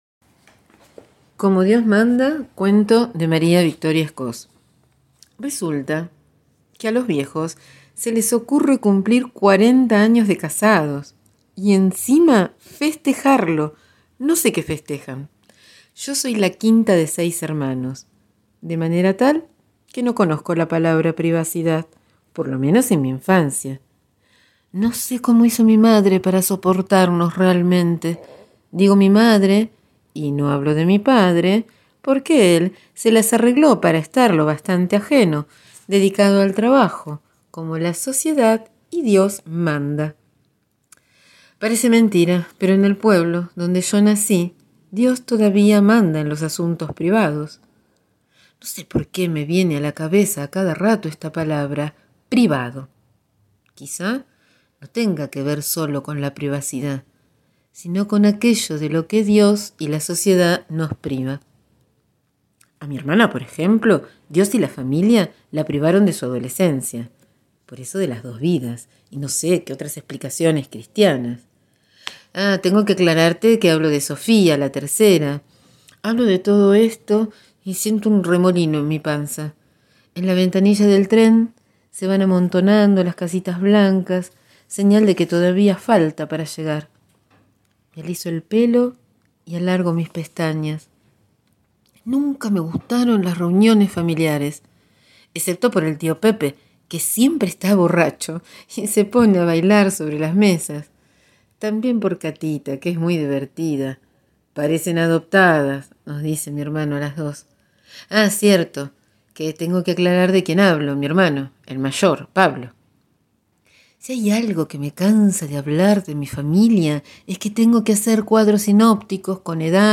Hoy comparto en audio la lectura del texto «Como dios manda», cuento que leímos en el encuentro del 29 de abril en «La casona de Humahuaca«.